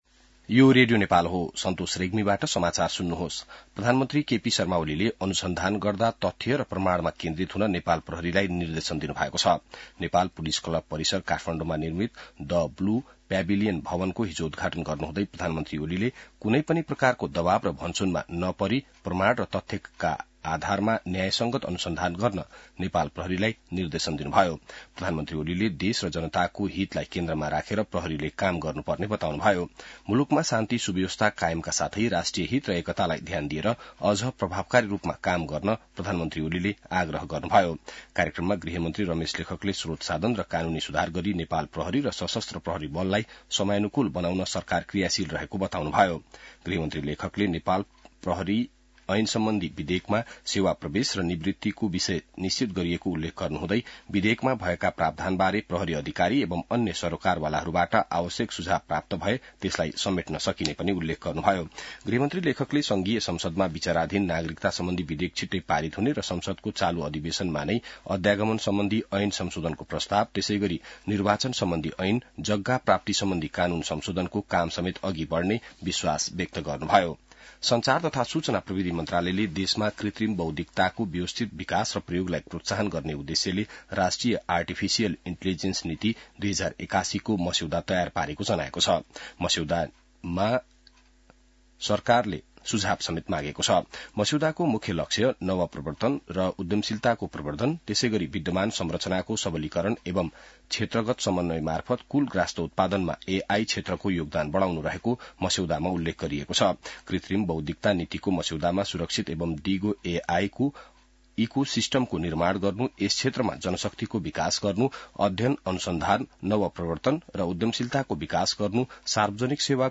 बिहान ६ बजेको नेपाली समाचार : २८ माघ , २०८१